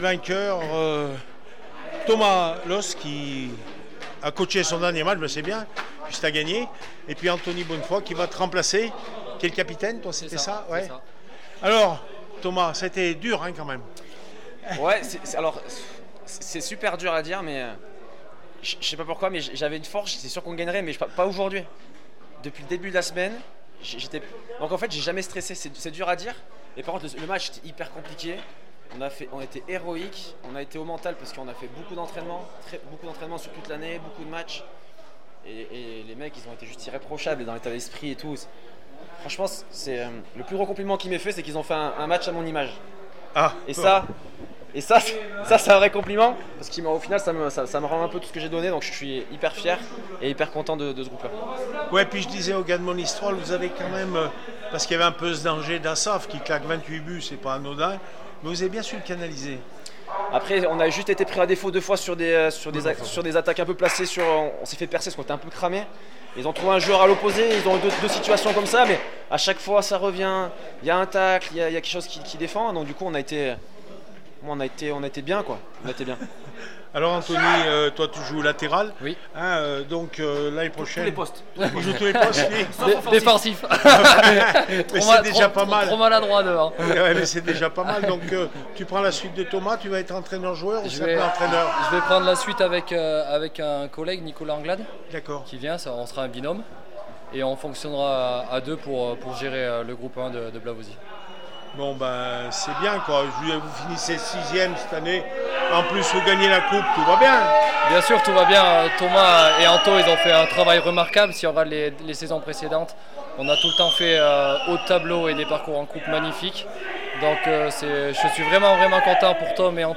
finale coupe de la hte Loire 2023 Blavozy 1-1 Monistrol sur Loire (8-7 tirs au but) réactions après match